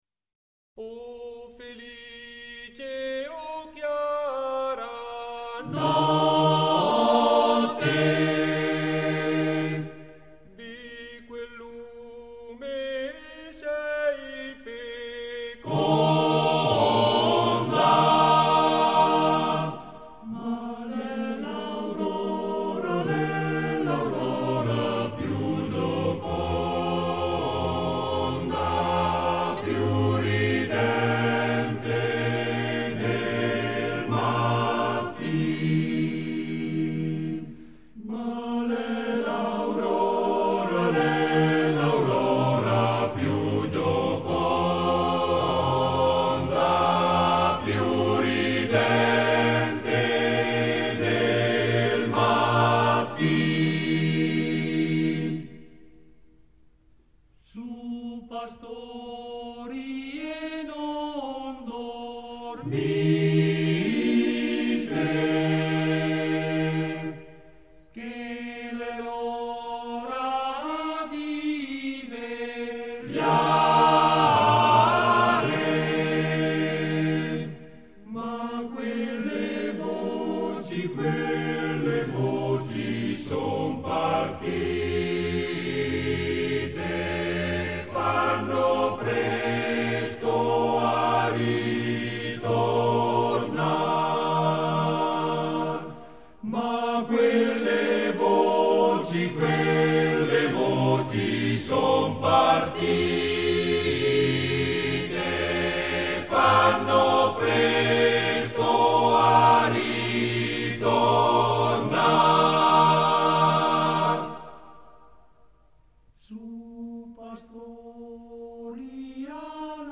Esecutore: Coro della SAT